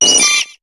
Cri de Chenipan dans Pokémon HOME.